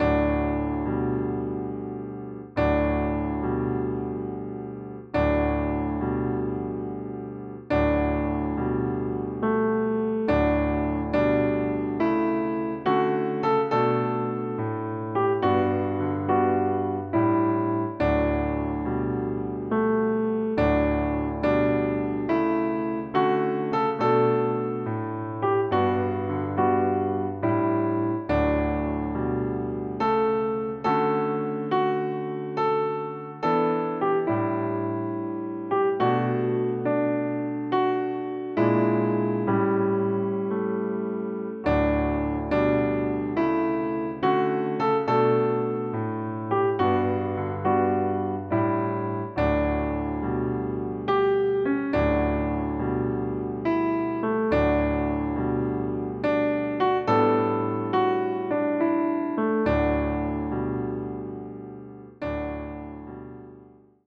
Besetzung: Klavier
zum jazzigen Walzer mit gospelmäßigen Klängen